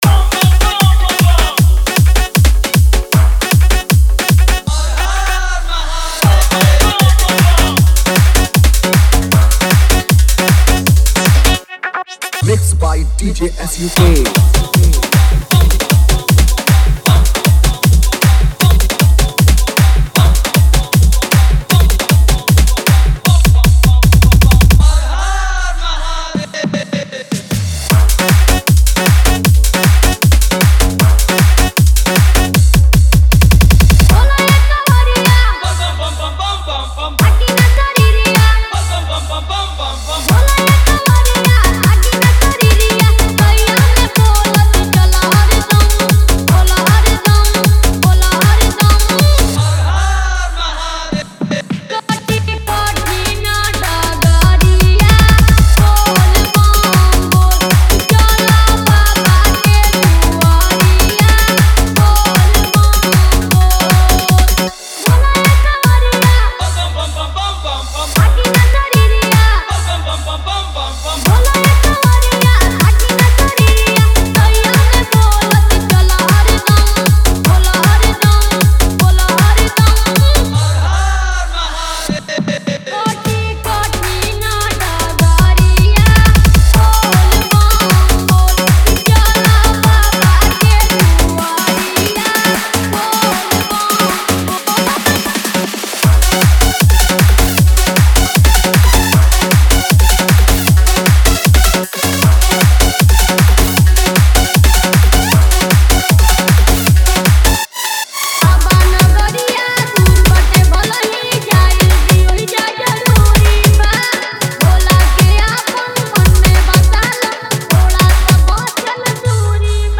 Category : Bhakti DJ Remix Songs